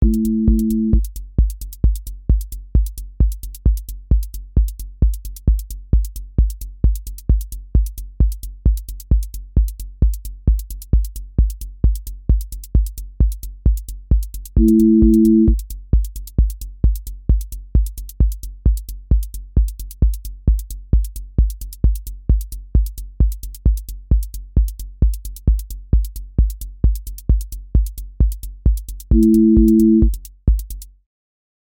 techno hypnosis
techno pressure with driven motion
• voice_kick_808
• voice_hat_rimshot
• voice_sub_pulse
• tone_brittle_edge
• motion_drift_slow